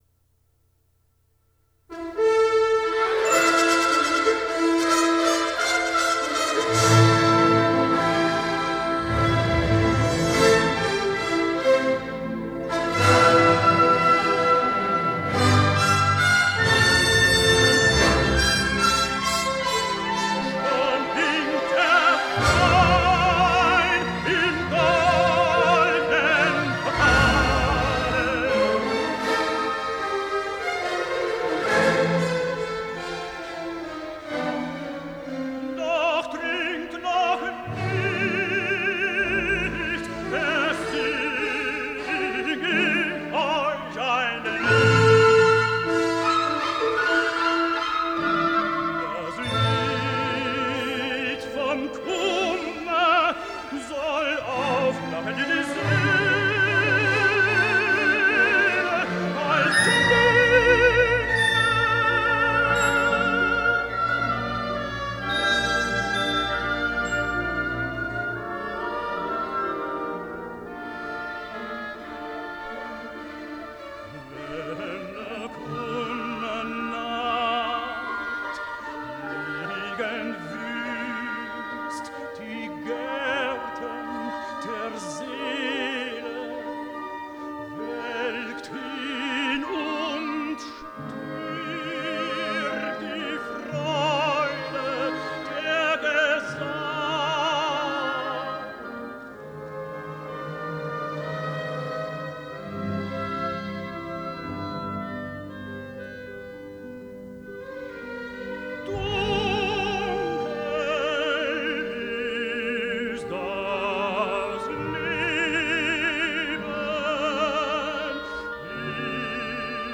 Mezzo-soprano Vocals
Tenor Vocals
Mastered in DSD128